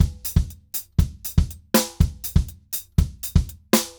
Drums_Baion 120_1.wav